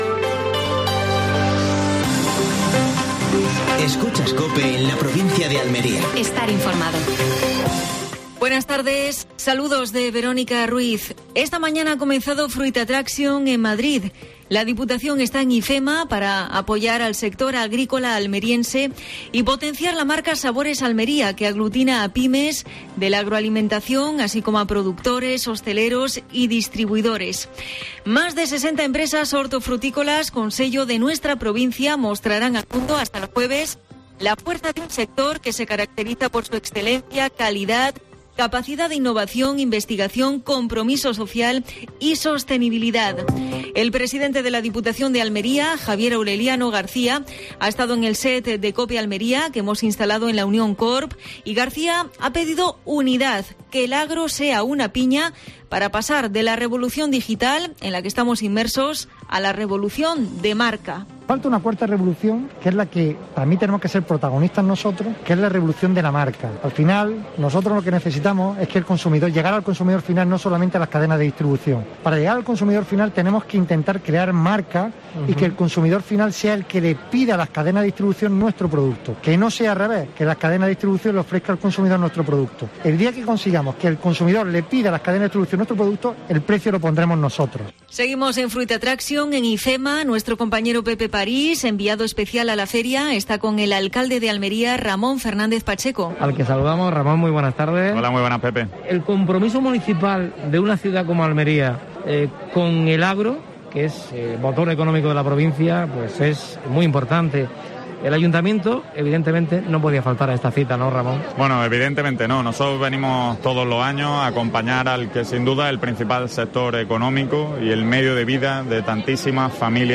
AUDIO: Última hora en Almería. Actualidad en Fruit Attraction. Entrevista a Ramón Fernández-Pacheco (alcalde de Almería).